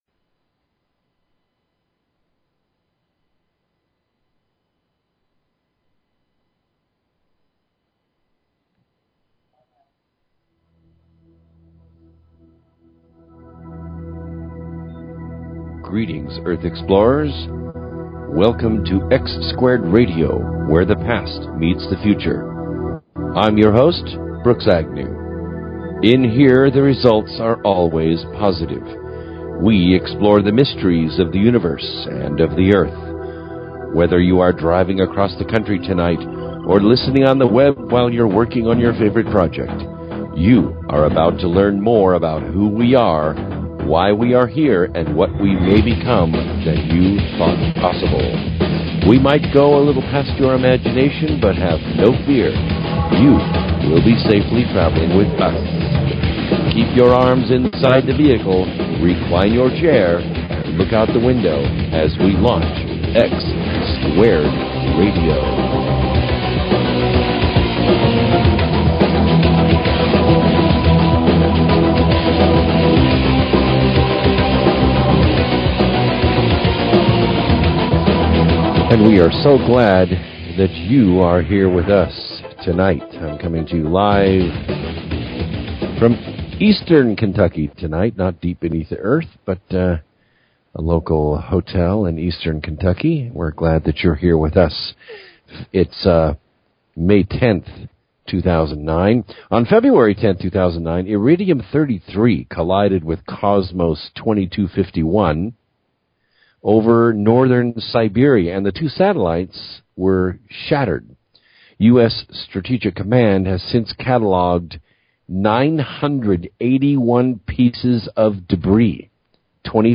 Talk Show Episode, Audio Podcast, X-Squared_Radio and Courtesy of BBS Radio on , show guests , about , categorized as
2009 Another open lines night where the caller came through.